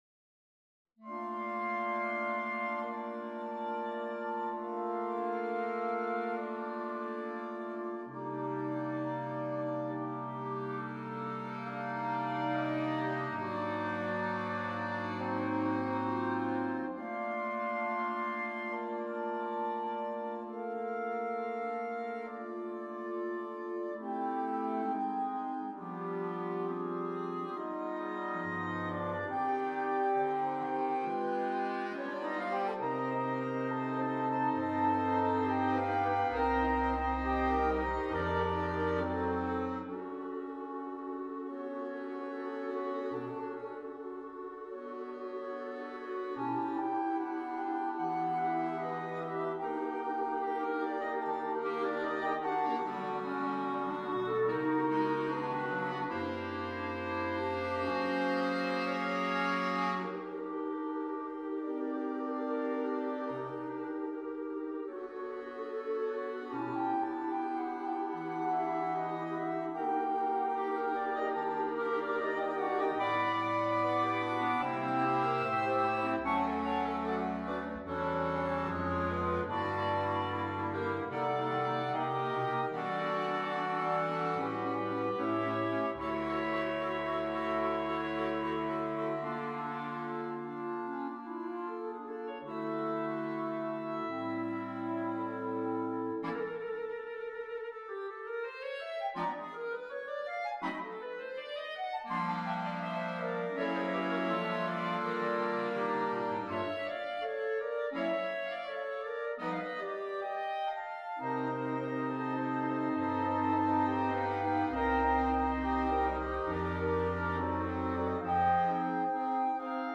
MUSICA DA CAMERA
CORO DI CLARINETTI